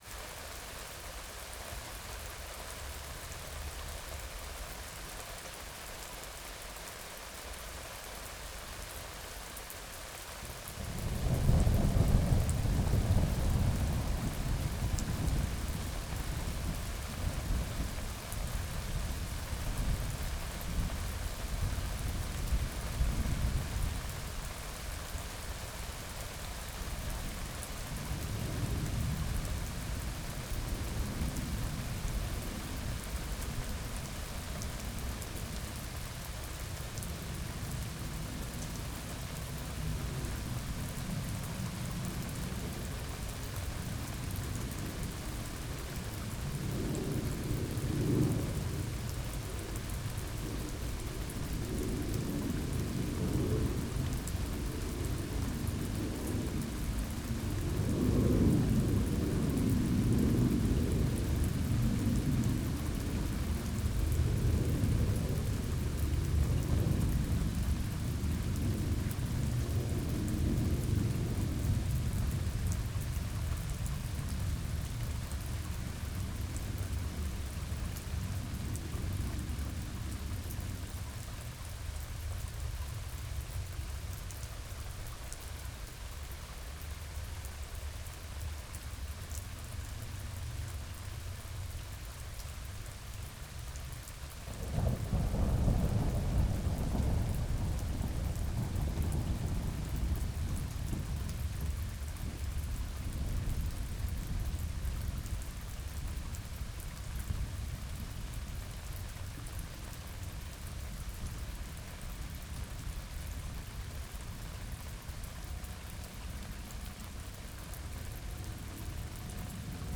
ONGELEGEN — Rain, Thunder and Airplanes Ambience (Binaural)